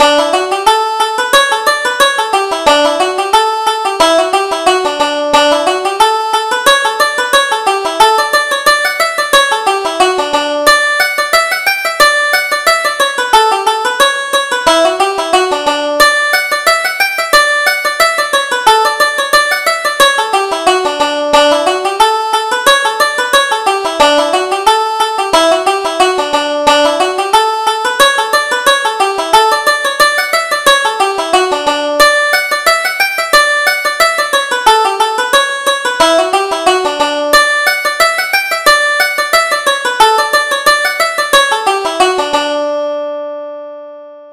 Reel: My Honey Is in the House